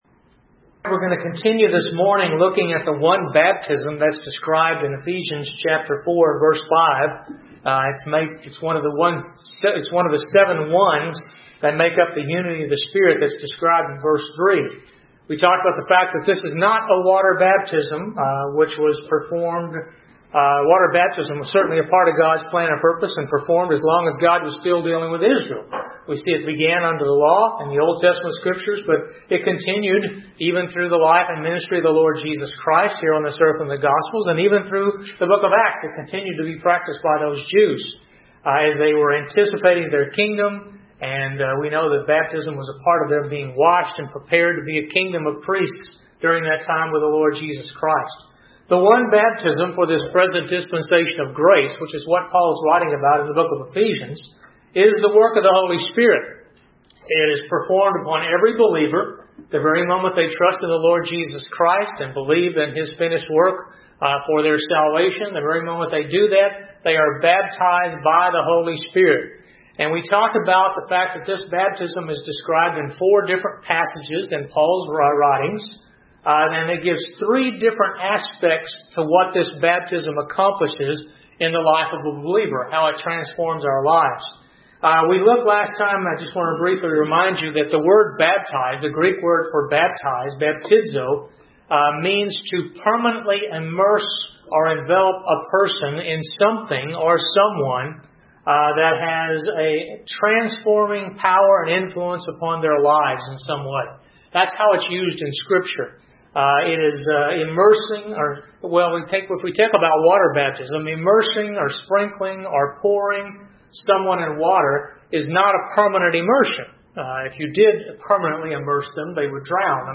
These lessons were given during the adult Sunday school class at Grace Bible Church in 2021.